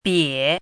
chinese-voice - 汉字语音库
bie3.mp3